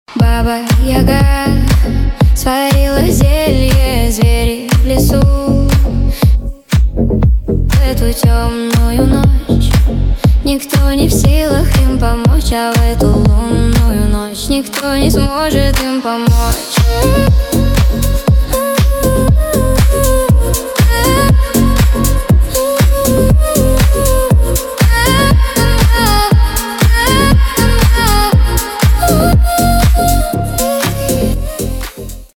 женский голос
Танцевальные рингтоны